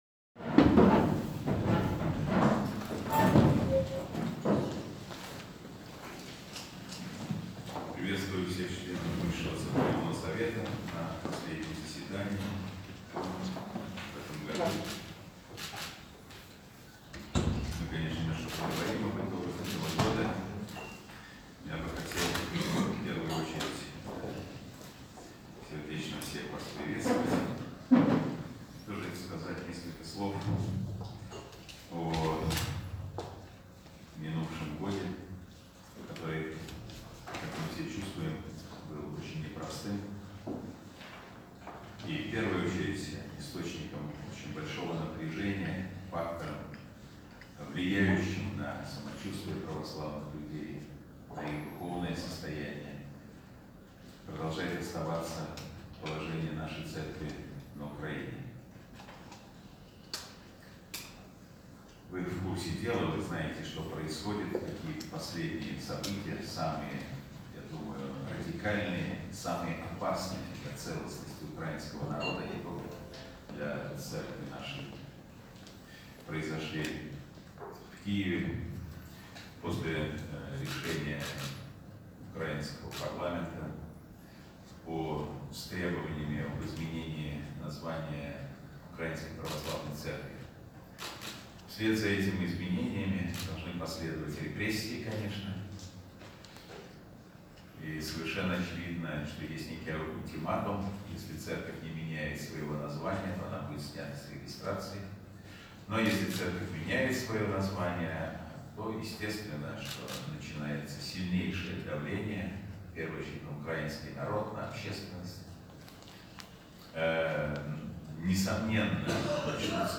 Ανταπόκριση από Μόσχα